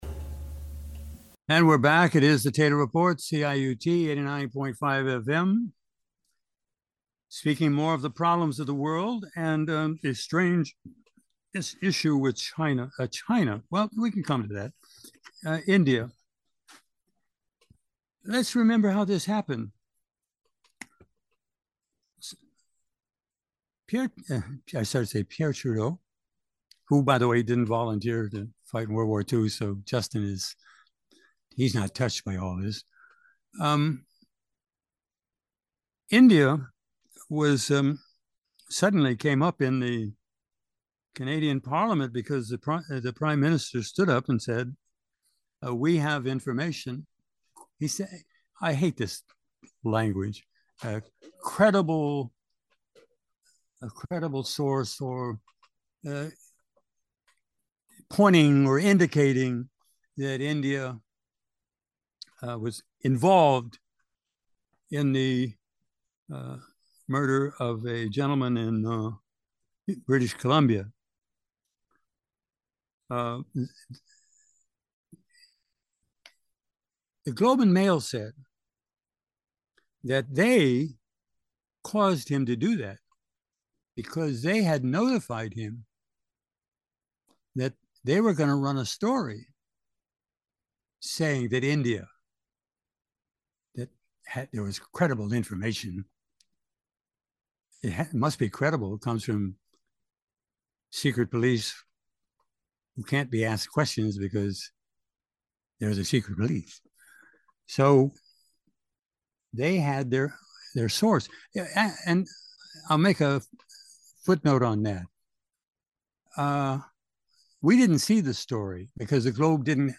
Trudeau's India problem Subtitle: Program Type: 7 Speakers: Taylor Report Commentary Contributor: ?? < Version: 1 Taylor Report commentary Version Description: Click on the Red Circle with the White Arrow in order to Listen Version Length: 12:22 a.m. Date Recorded: Oct. 2, 2023 1: 12:22 a.m. - MB download